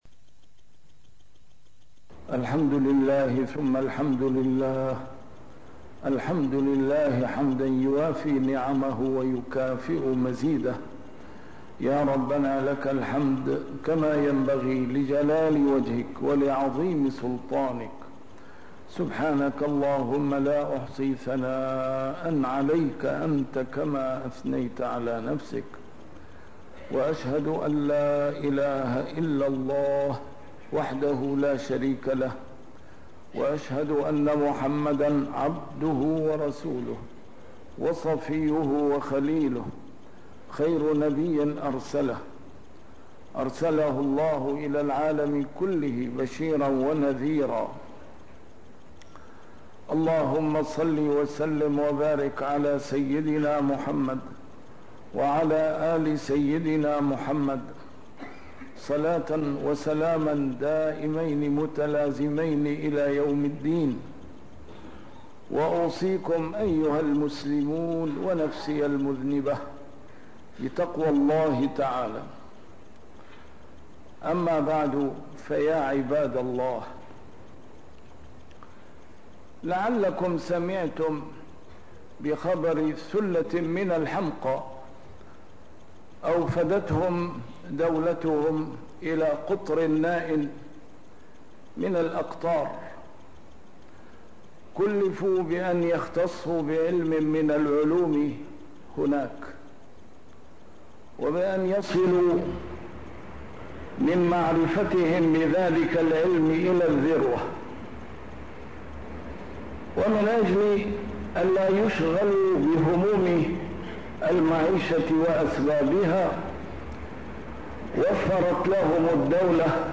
A MARTYR SCHOLAR: IMAM MUHAMMAD SAEED RAMADAN AL-BOUTI - الخطب - قصة الحمق .. تتكرر عبر الأجيال